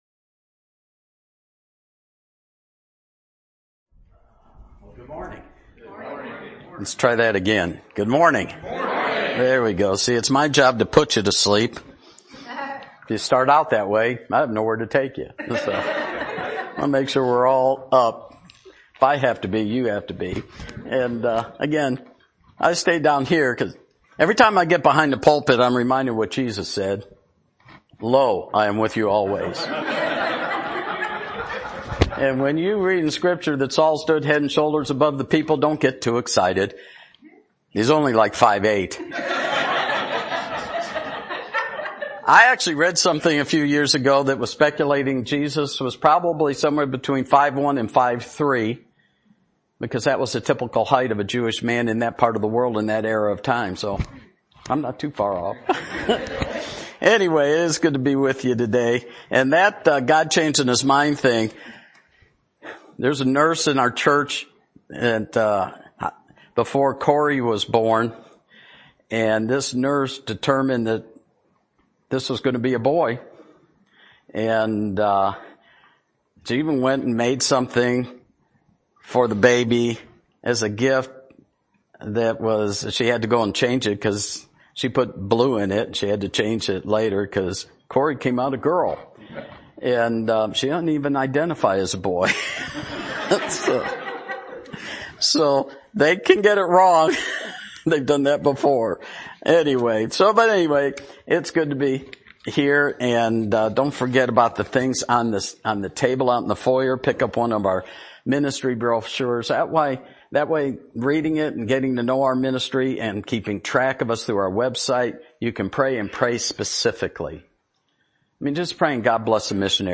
” Testimony”